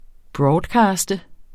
Udtale [ ˈbɹɒːdˌkɑːsdə ]